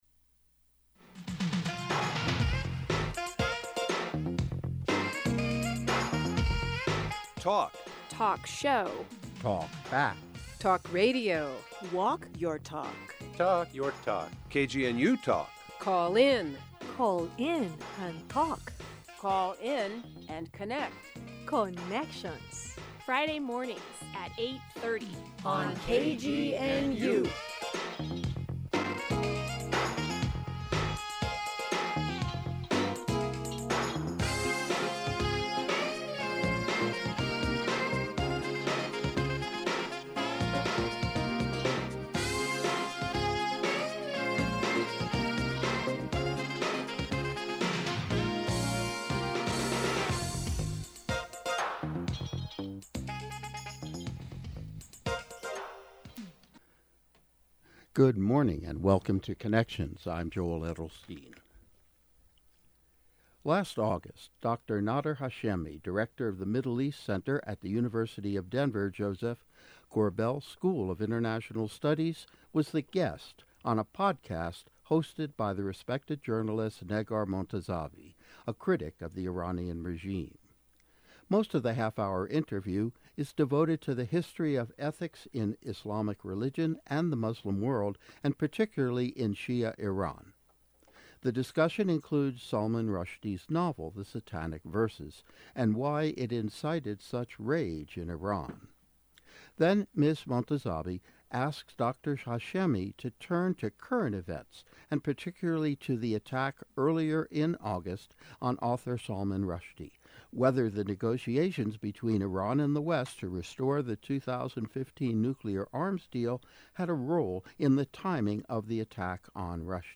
In this interview produced by KGNU’s